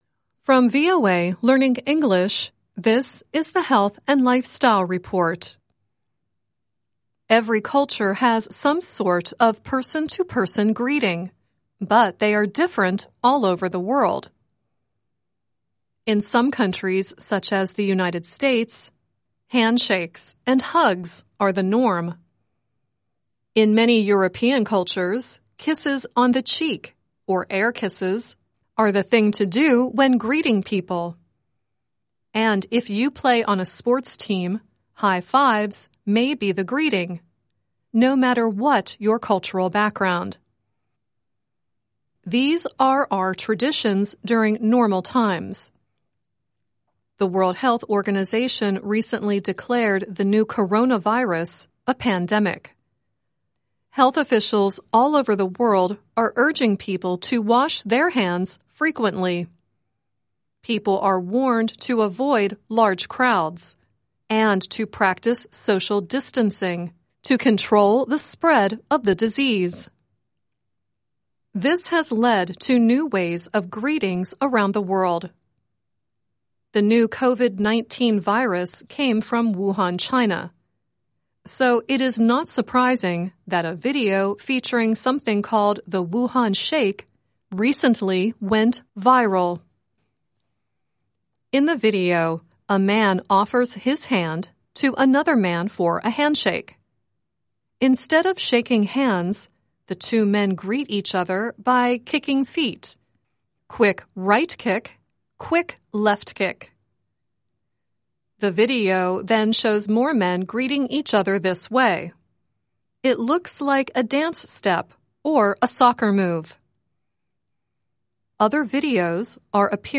Health & Lifestyle